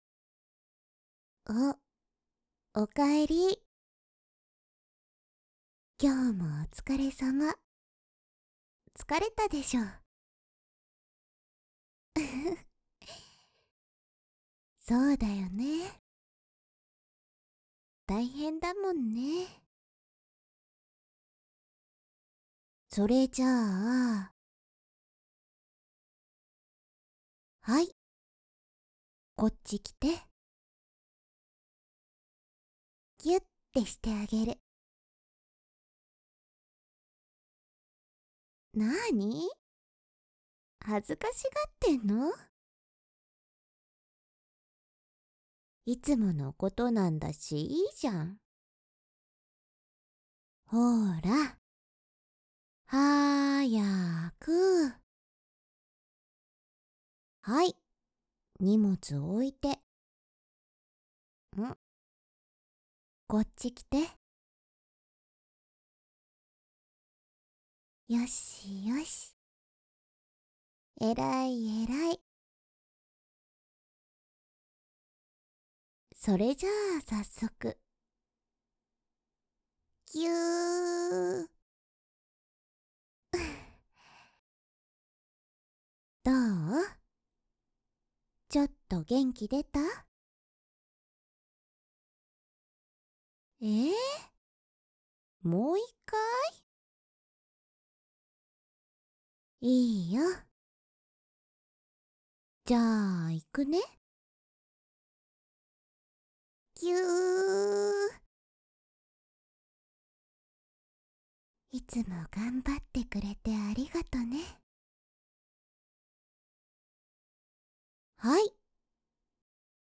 纯爱/甜蜜 日常/生活 温馨 萌 健全 治愈 皆大欢喜 催眠音声